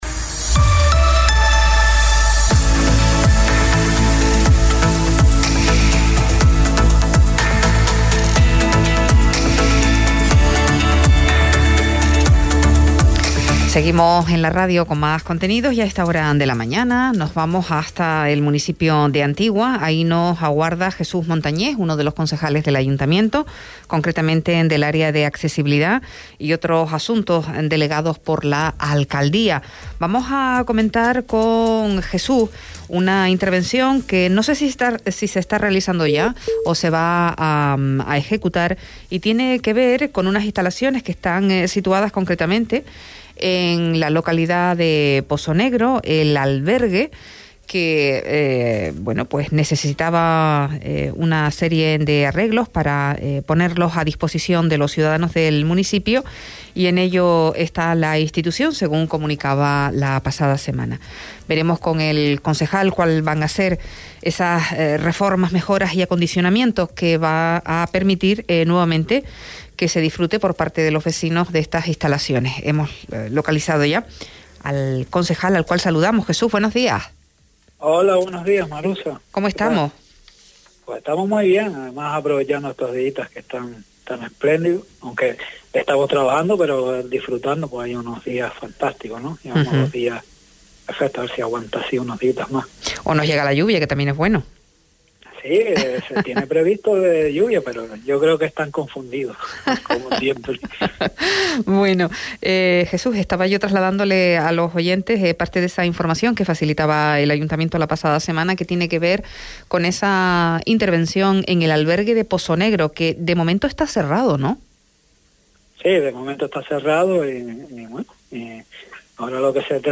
Entrevista a Jesús Montañez concejal de Antigua - Radio Sintonía
Entrevistas